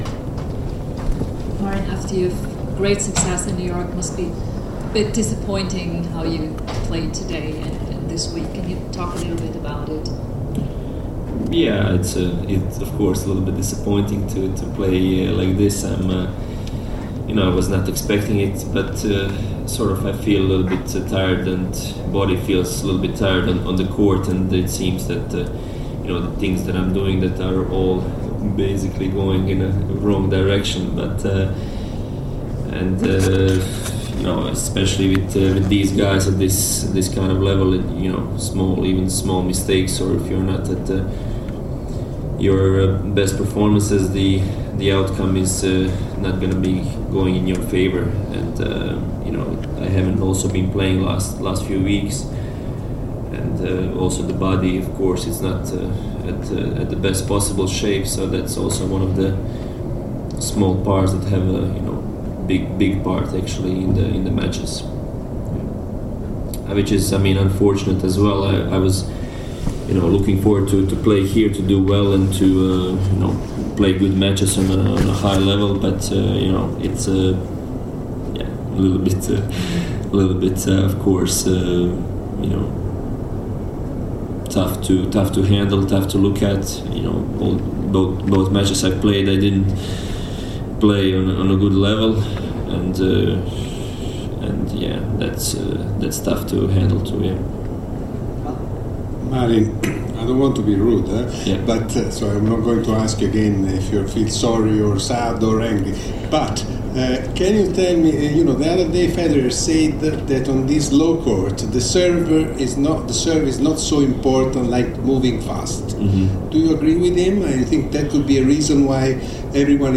Conferenza stampa Cilic